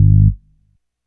Bass (17).wav